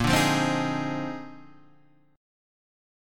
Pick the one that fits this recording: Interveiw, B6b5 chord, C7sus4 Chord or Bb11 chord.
Bb11 chord